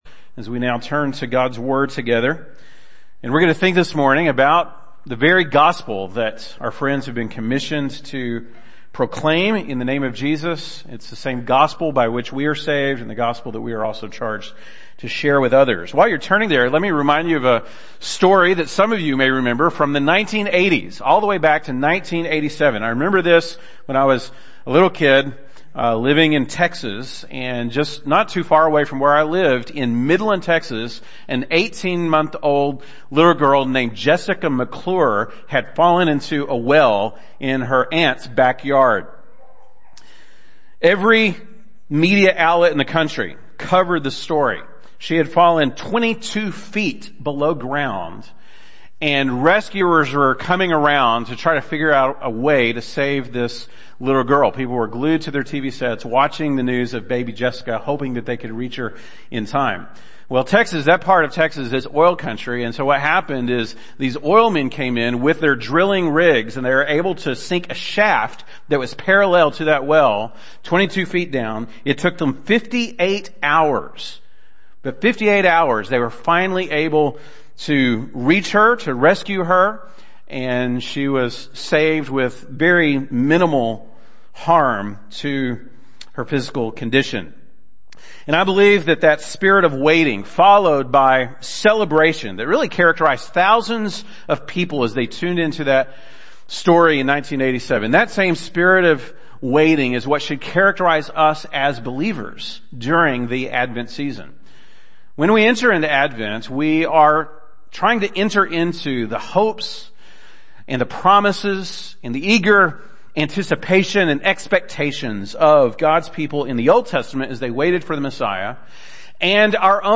December 11, 2022 (Sunday Morning)